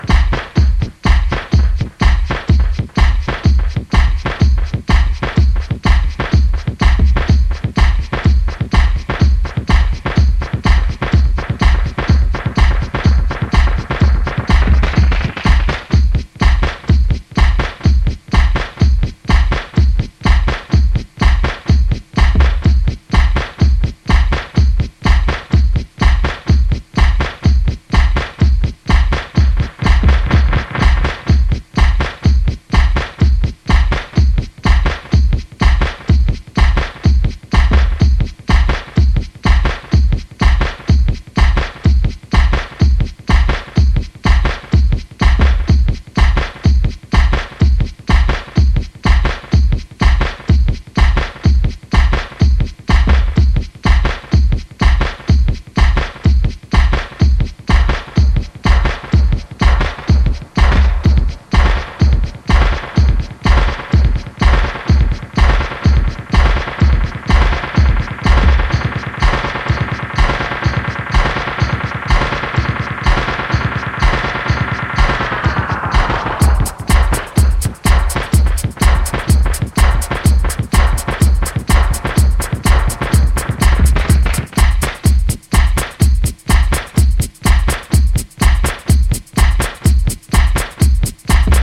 a more stripped back, lo fi, floor focussed sound
lo-fi, minimal DJ tools full of character and a quirkiness